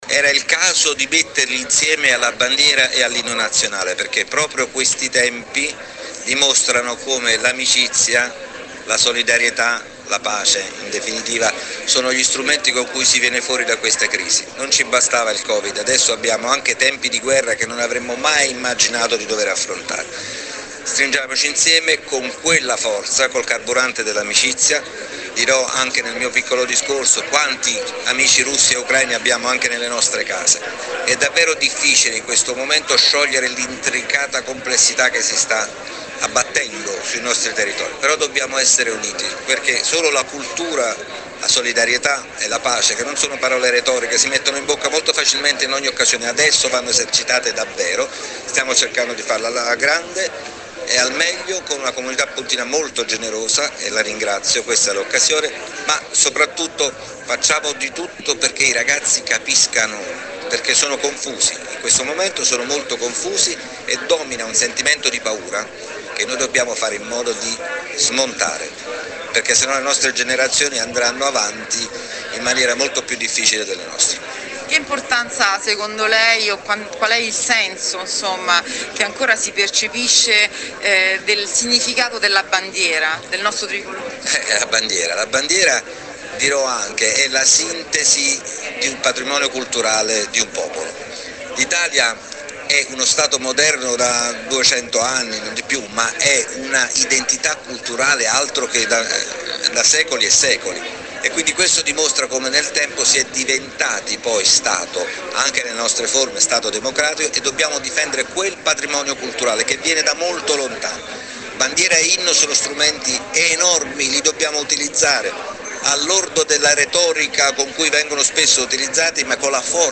prefetto-bandiera-e-inno.mp3